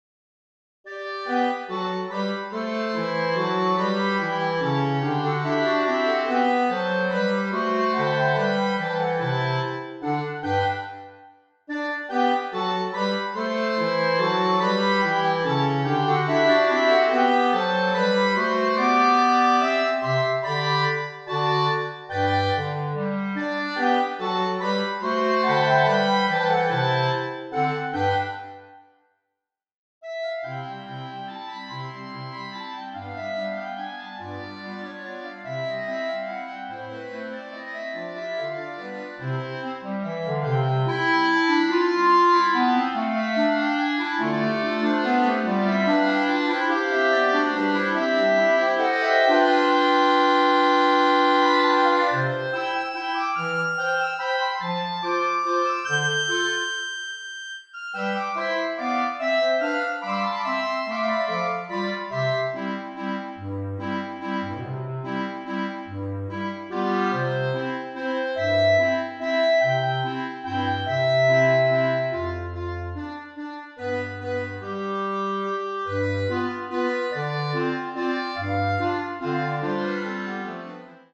Klarinettenquartett/Saxophonquartett